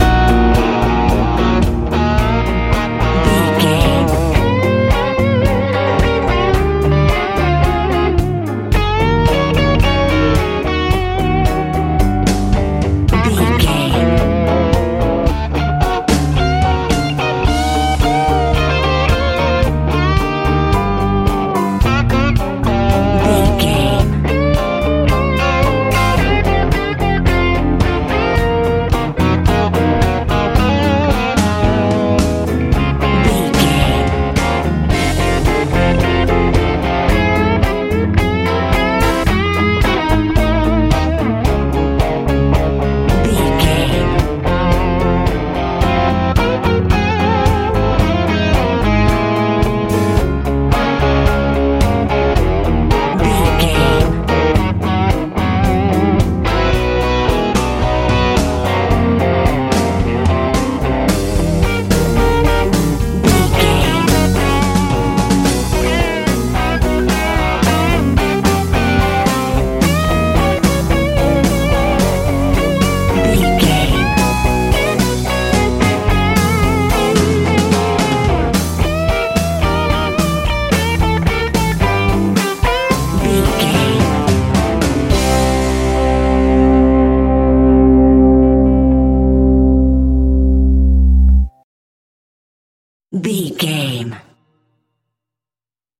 Ionian/Major
magical
strange
piano
drums
bass guitar
electric guitar
happy